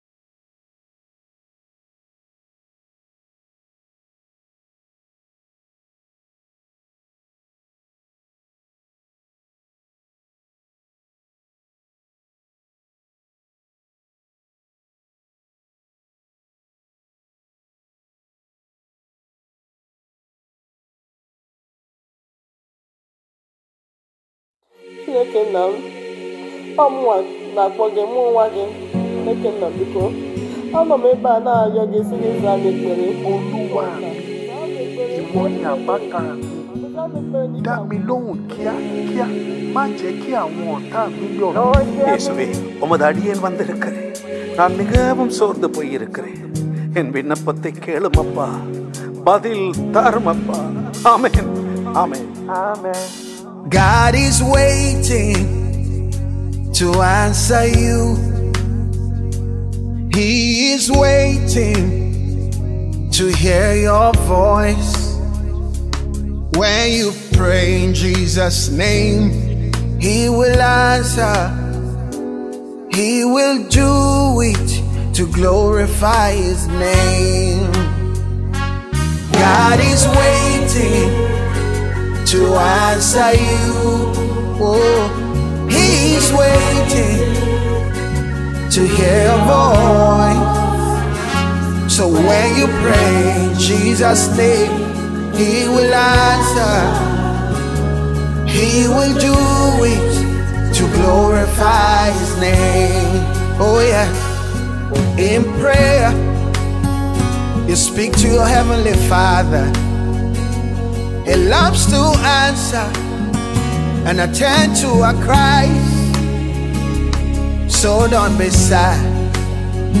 MFM BOWIE MD WEEKLY PRAYER MEETING -WATCH AND PRAYER – Podcast